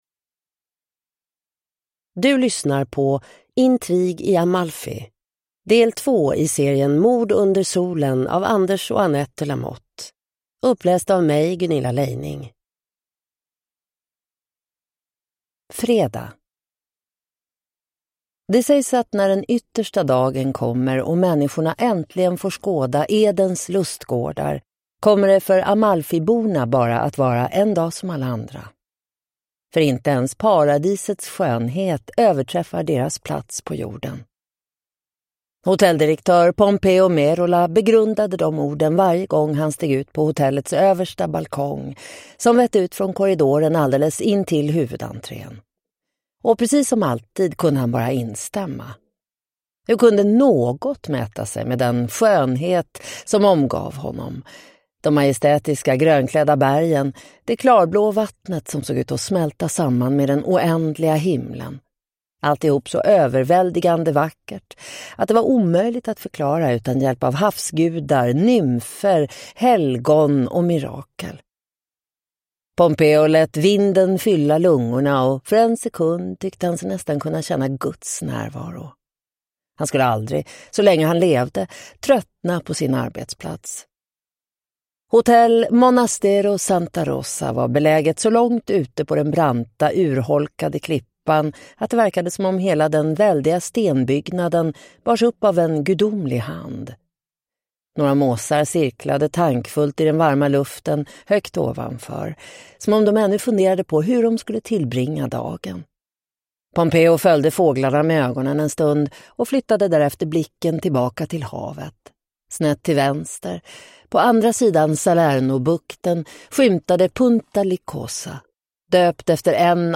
Intrig i Amalfi (ljudbok) av Anders De la Motte